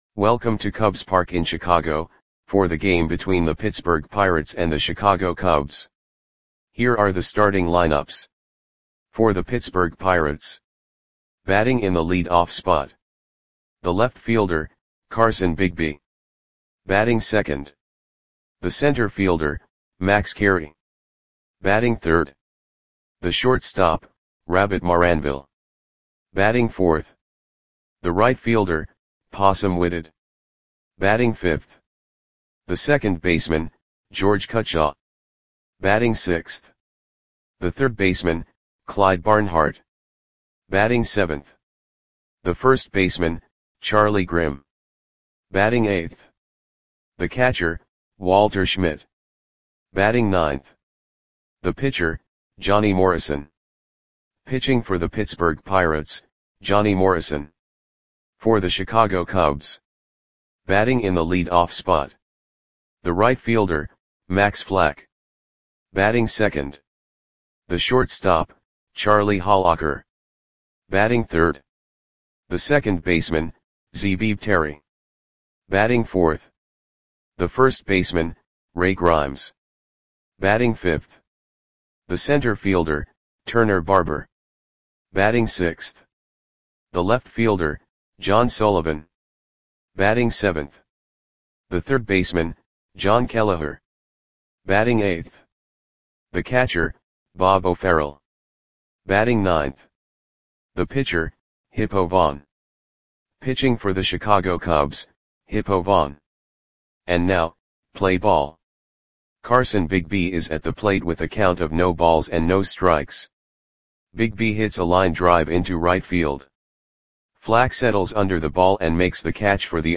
Audio Play-by-Play for Chicago Cubs on June 27, 1921
Click the button below to listen to the audio play-by-play.